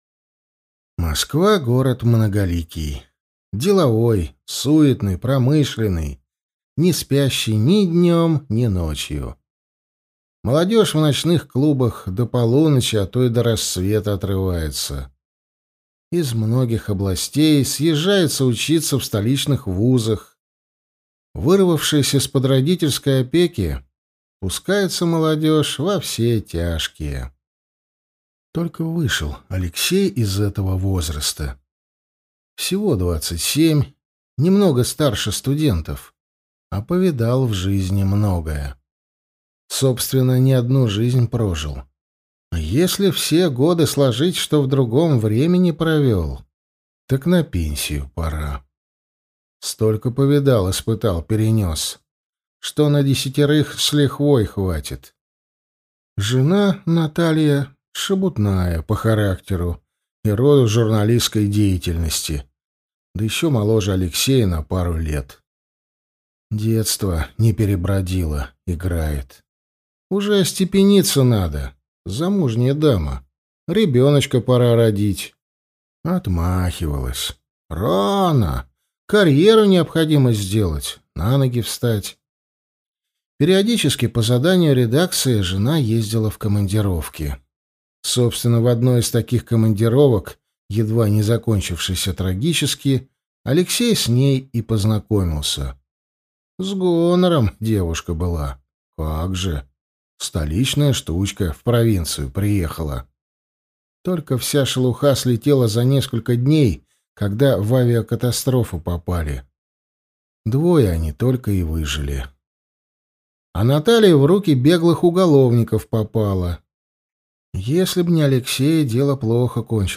Аудиокнига Двурогий. Попаданец к Александру Македонскому | Библиотека аудиокниг